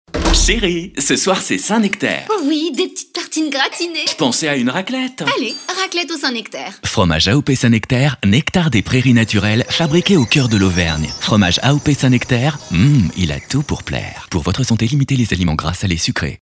SAINT NECTAIR comédie - Comédien voix off
Genre : voix off.